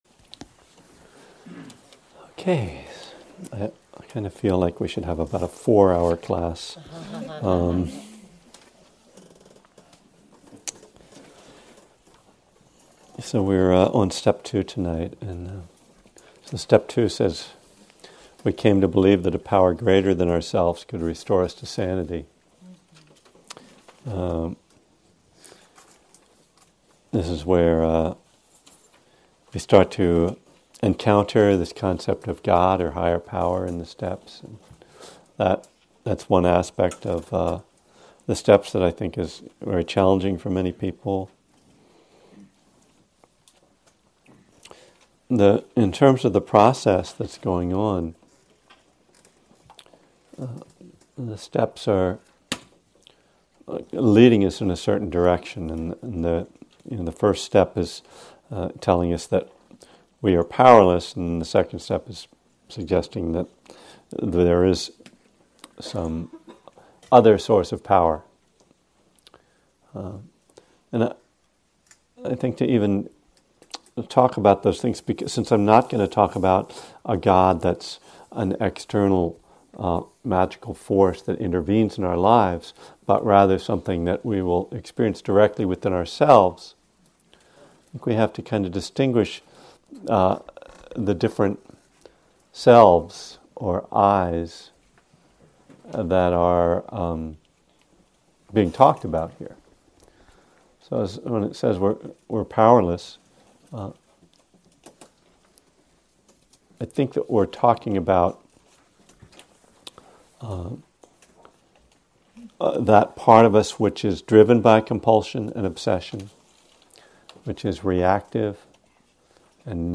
From a Buddhism and the Twelve Steps class series in 2011 at Spirit Rock Meditation Center.